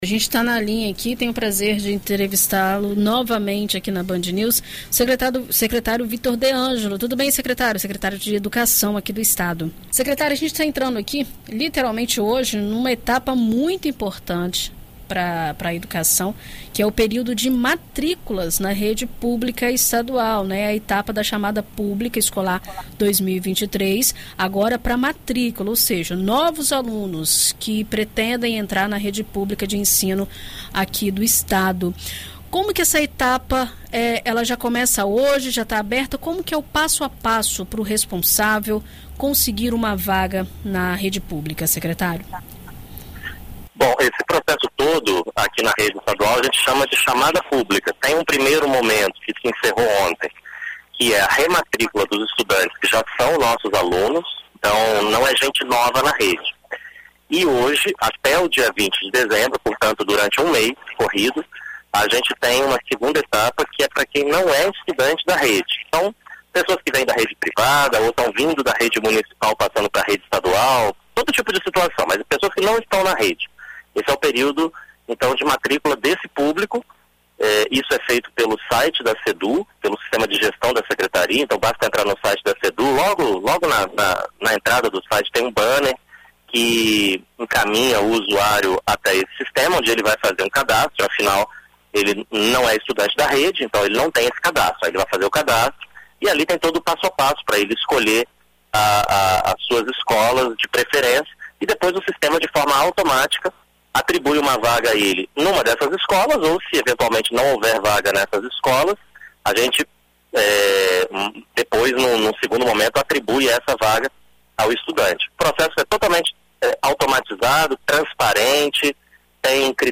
Na BandNews FM Espírito Santo, o secretário de Estado de Educação, Vitor de Ângelo, explica como realizar a matrícula e conversa sobre o processo.
Na última segunda-feira (21) terminou o prazo para solicitar a rematrícula, que é a primeira etapa do período de matrícula. Em entrevista à BandNews FM Espírito Santo nesta terça-feira (22), o secretário de Estado de Educação, Vitor de Ângelo, explica como realizar a matrícula e conversa sobre o processo.